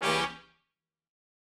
GS_HornStab-Gdim.wav